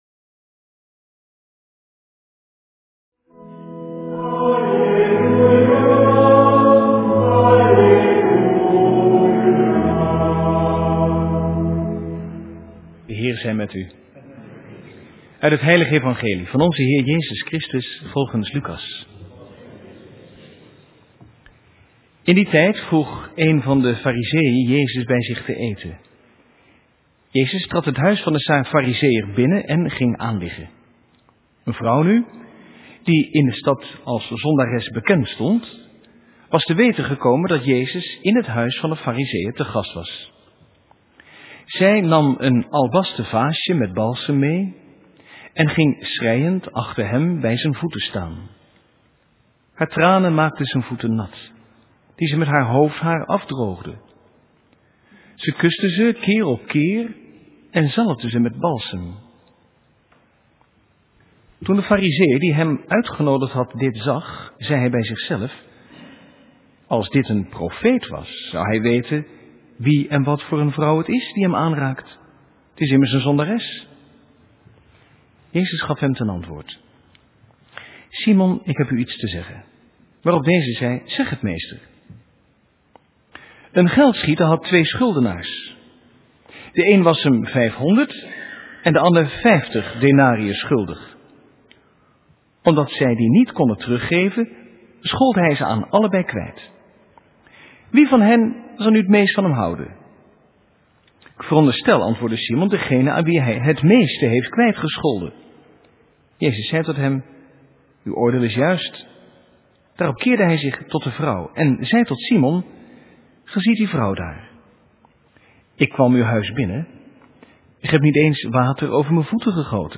Eucharistieviering beluisteren vanuit de St. Jozefkerk te Wassenaar (MP3)